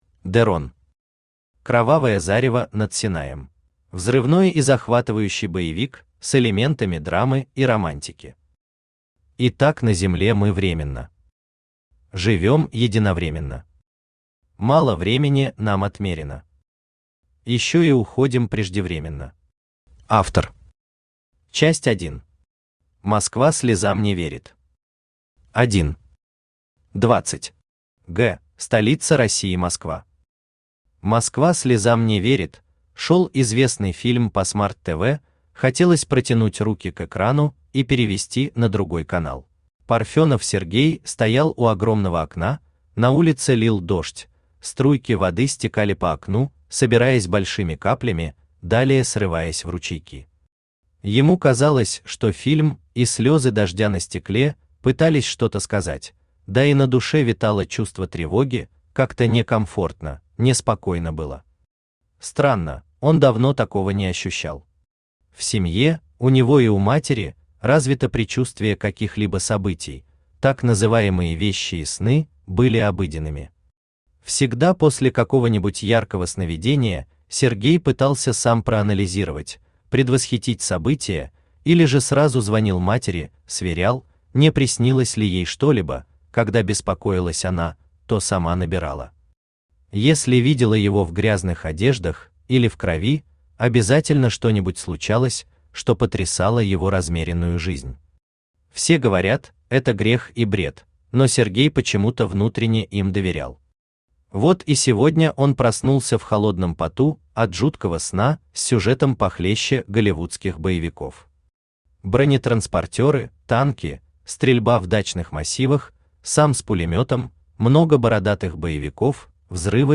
Аудиокнига Кровавое зарево над Синаем | Библиотека аудиокниг
Aудиокнига Кровавое зарево над Синаем Автор De Ron Читает аудиокнигу Авточтец ЛитРес.